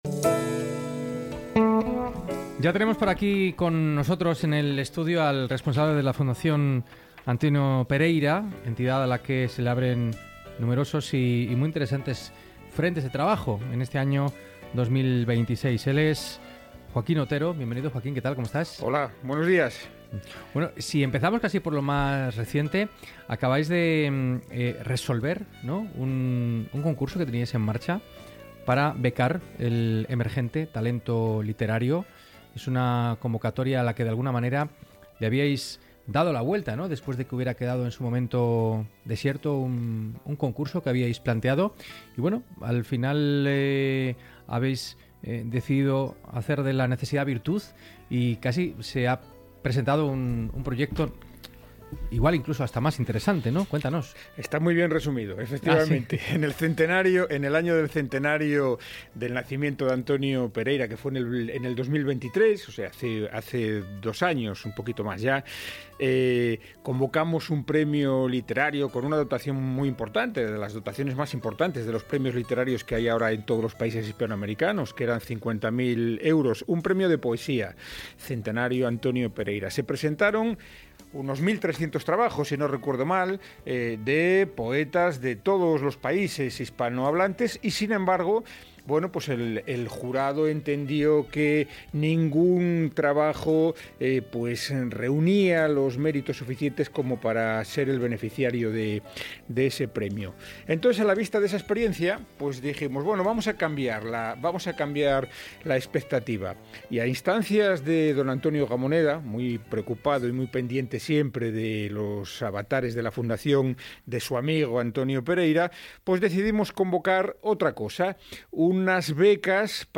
Programa de radio emitido en febrero de 2026 en la Cadena Ser